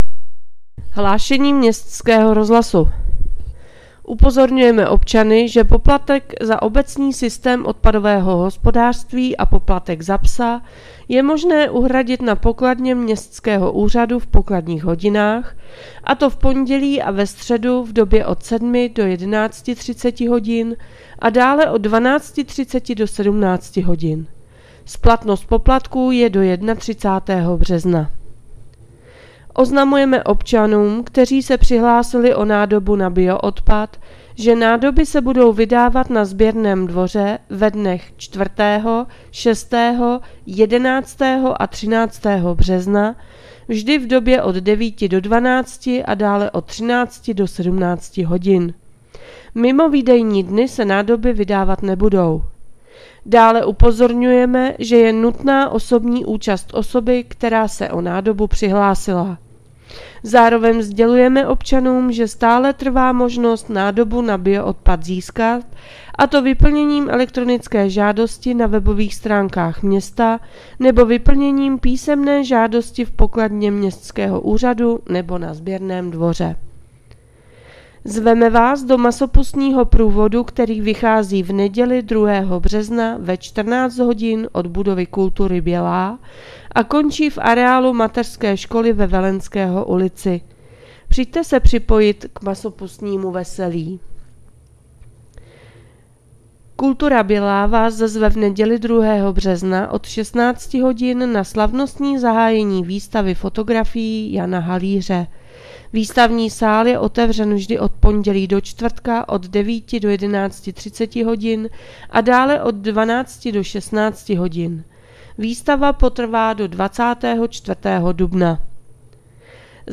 Hlášení městského rozhlasu 28.2.2025